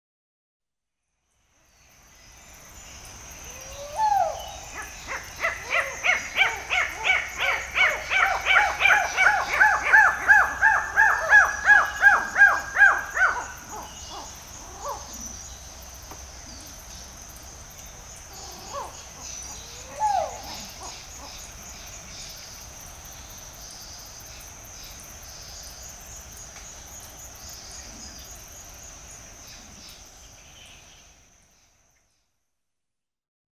Звуки лемура
Звуки лемурьих криков в тропическом лесу